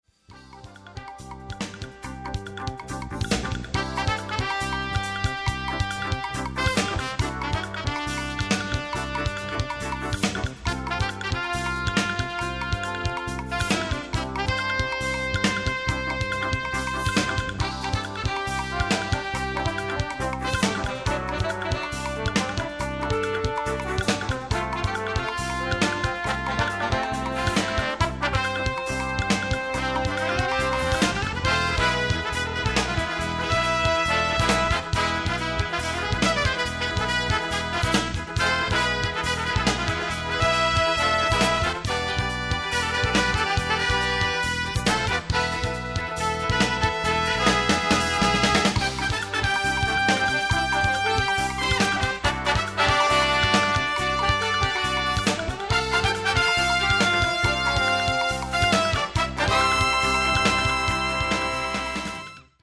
contemporary jazz instrumental
original Spanish-flavored composition